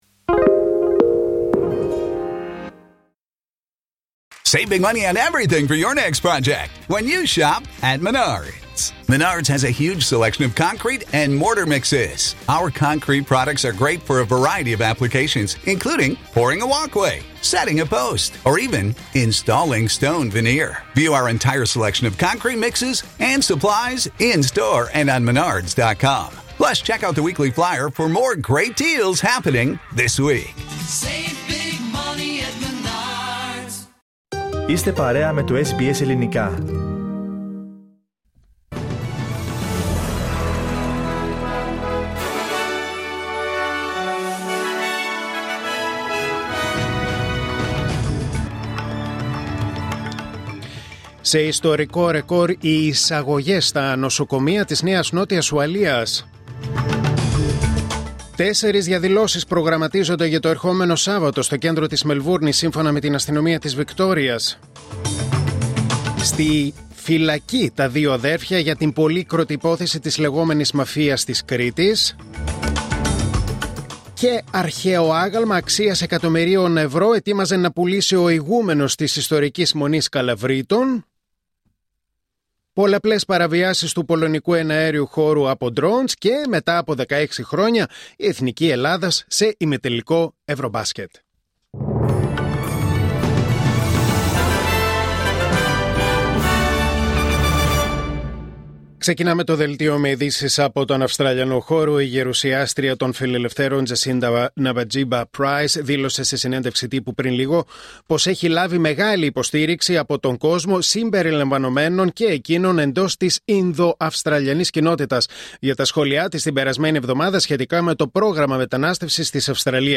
Δελτίο Ειδήσεων Τετάρτη 10 Σεπτεμβρίου 2025